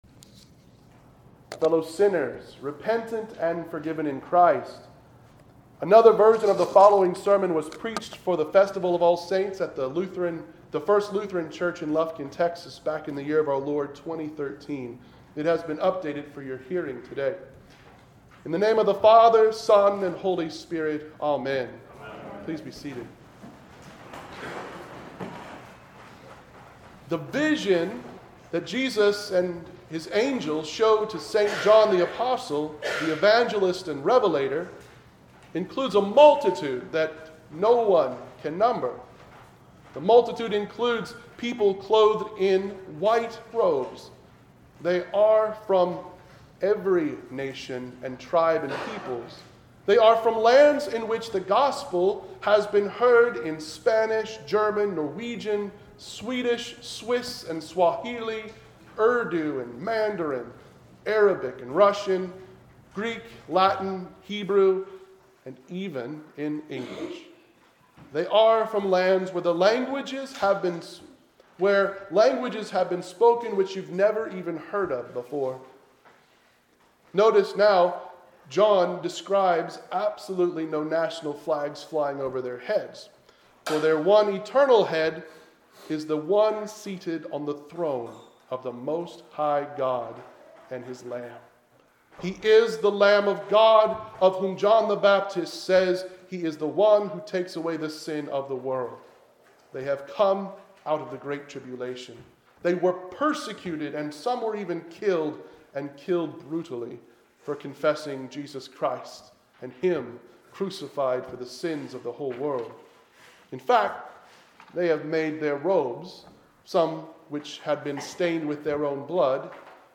sermon-for-the-festival-of-all-saints.mp3